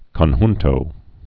(kŏn-hntō)